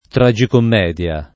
tragicommedia [ tra J ikomm $ d L a ] s. f.